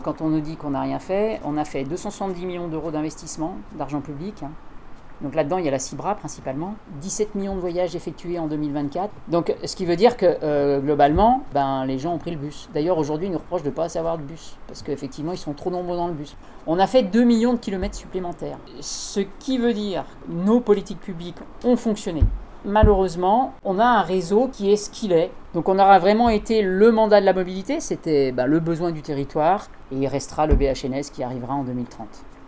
Frédérique Lardet s'explique aussi sur les avancées concernant les mobilités.